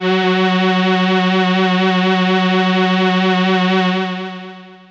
55bd-syn09-f#3.aif